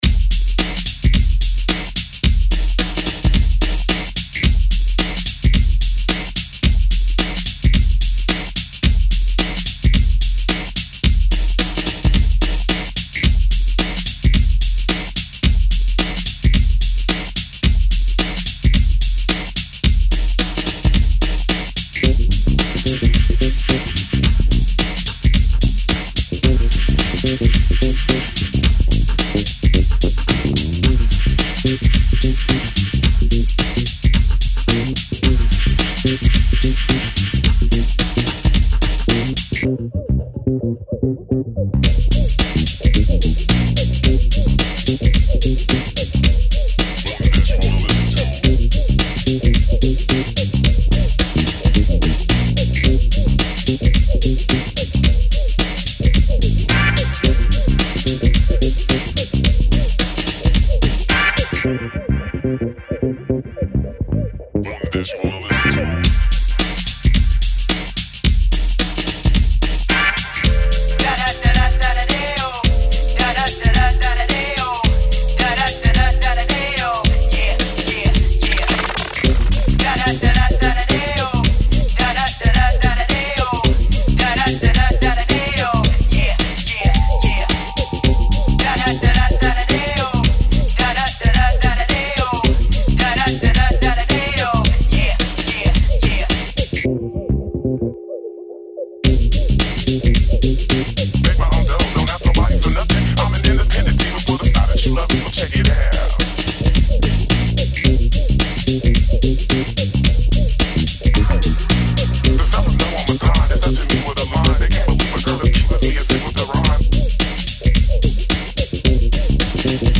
groove - urbain - entrain